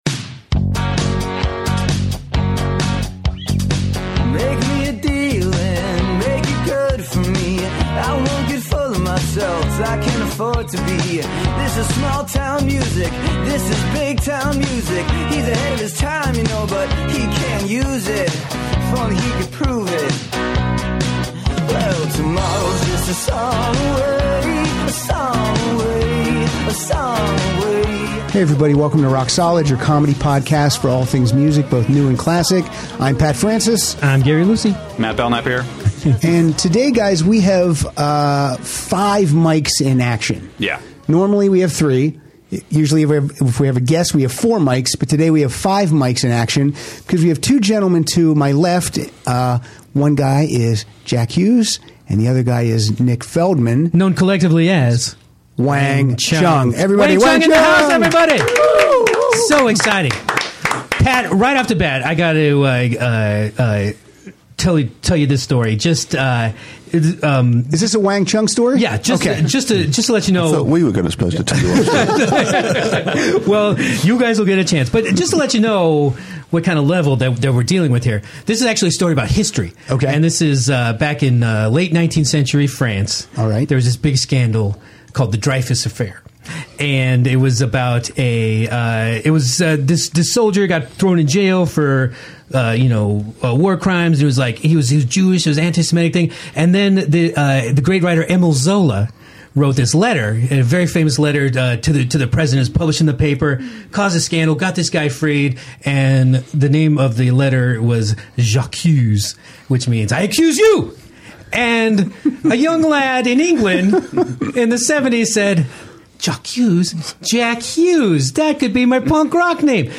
Everybody has fun in the studio as Jack Hues and Nick Feldman sit in to discuss the '80s and beyond.